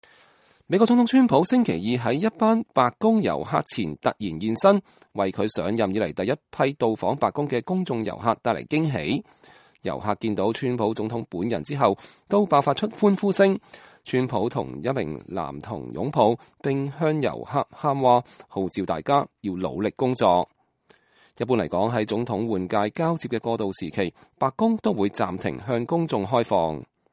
遊客見到川普總統本人後爆發出歡呼聲。